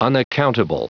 Prononciation du mot unaccountable en anglais (fichier audio)
Prononciation du mot : unaccountable